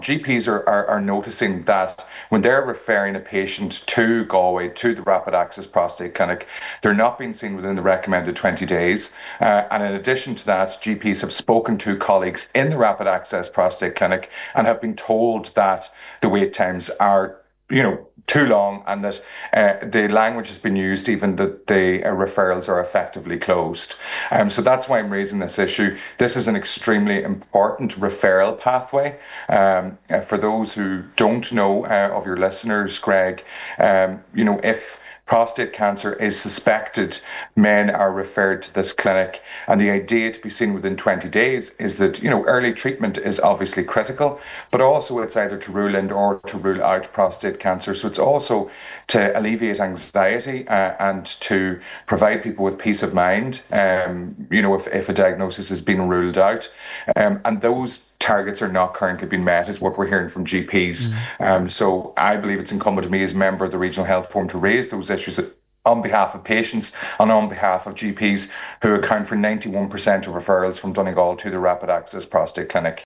Cllr Meehan says when it comes to cancer diagnoses, time is of the essence: